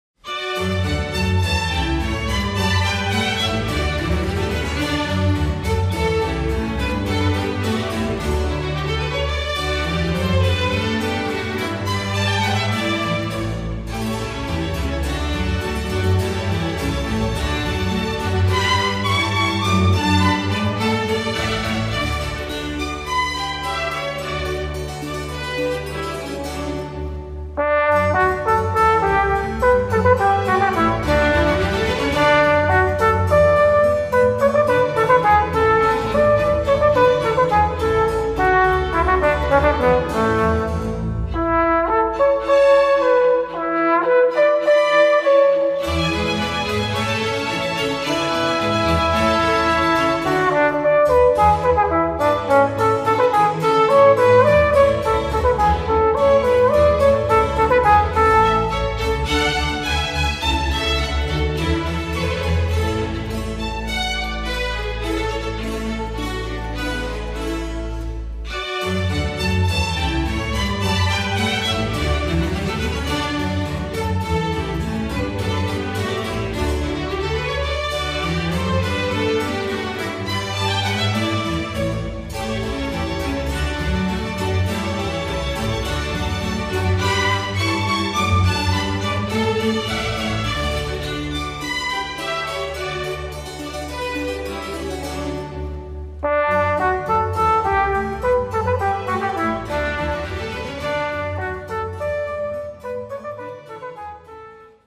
Voicing: Orchestra